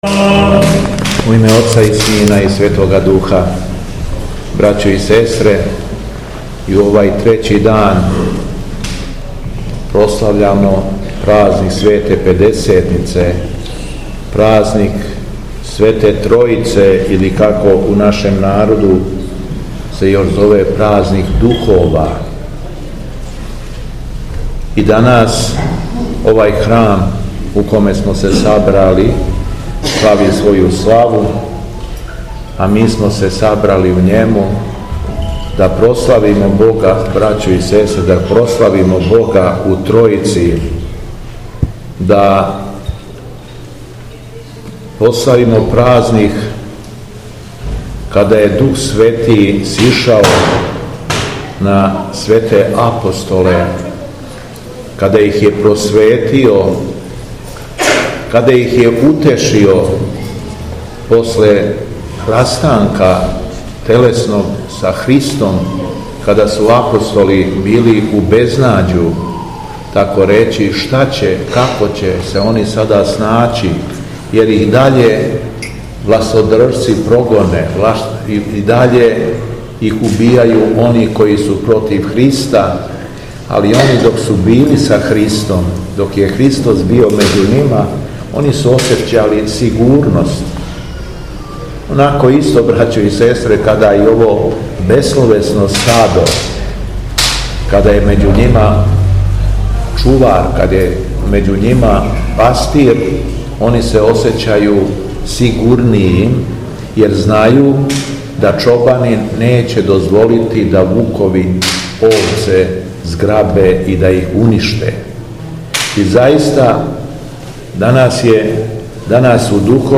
Беседа Његовог Преосвештенства Епископа шумадијског г. Јована
У уторак, 6. јуна 2023. године, на Духовски уторак, Његово Преосвештенство Епископ шумадијски г. Јован служио је Свету архијерејску Литургију у Цркви Свете Тројице у крагујевачком насељу Церовац.